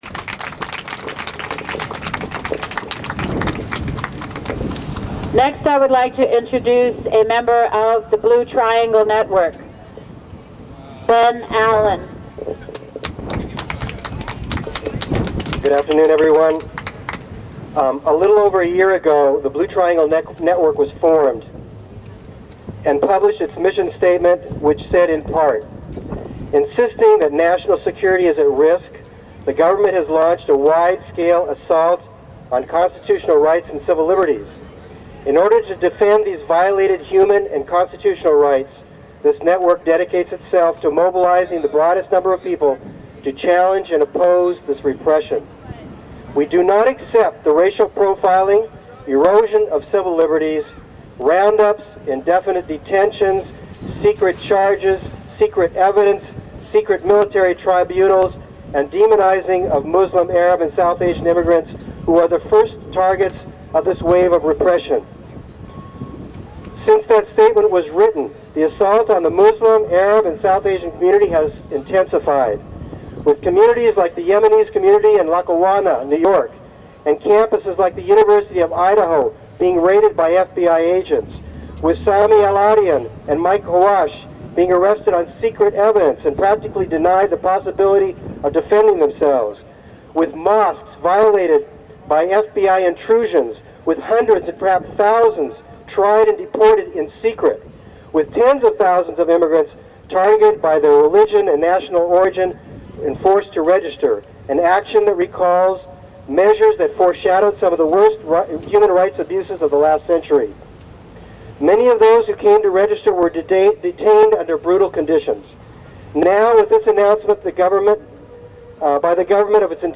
This footage is from the protest in front of the INS building that took place from noon to 1pm at 444 Washington Street in San Francisco on June 13, 2003.